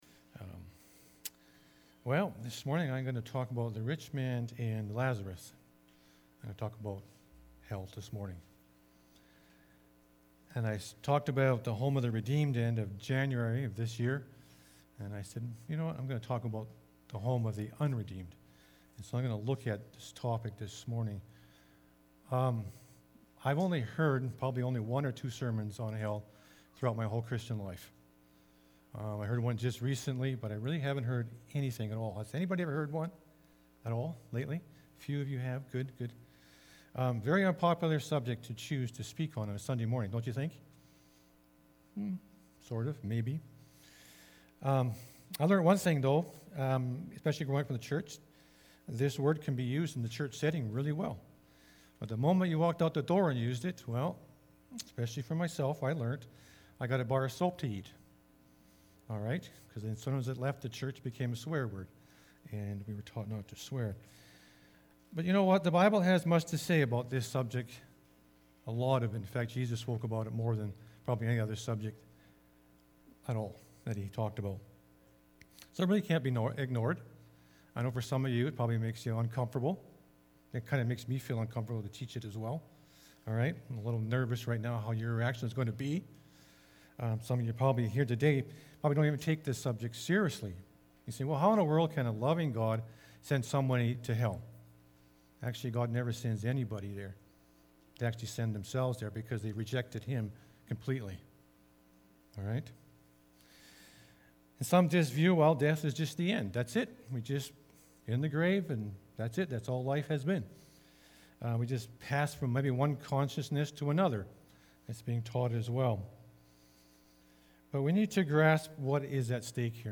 May-15-sermon-audio.mp3